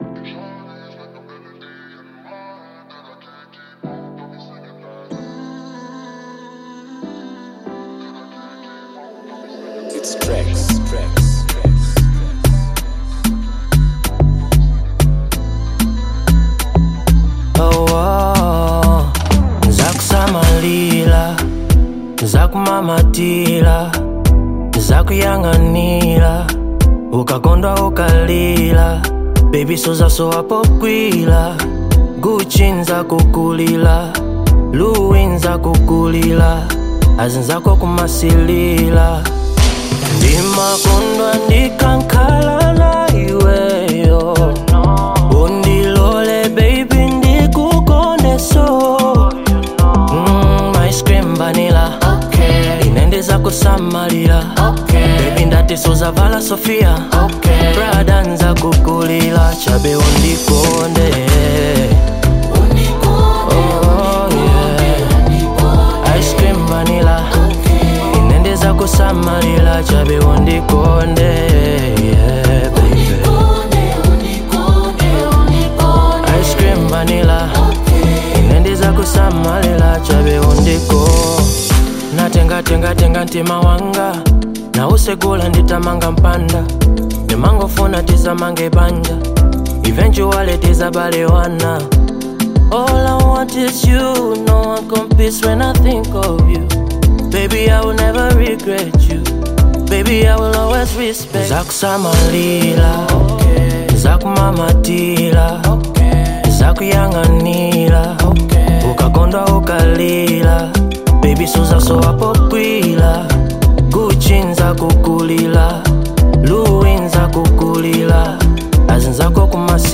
AfrobeatAudioMalawian Music
heartfelt Afro-Pop/Afrosoul single